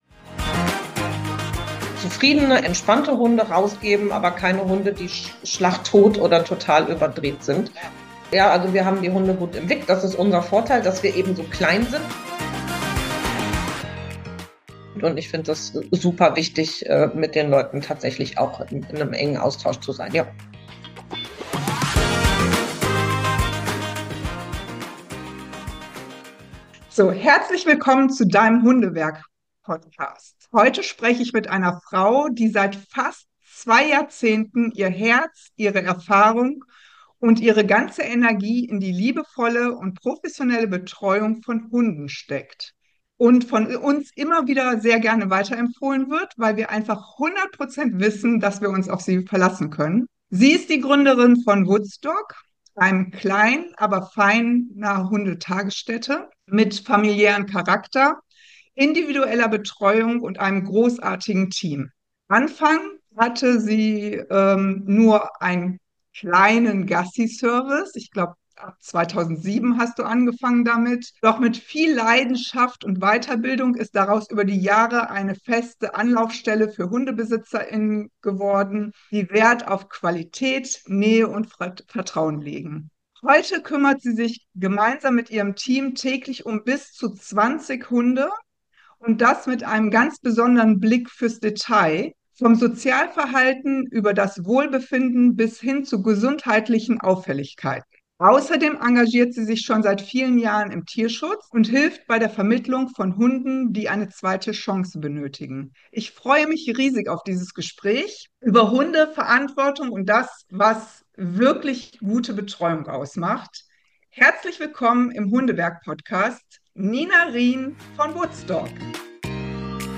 Vom kleinen Gassiservice zur professionellen Hundetagesstätte mit familiärem Charakter: Ein Gespräch über Vertrauen, Verantwortung und echte Hundeliebe.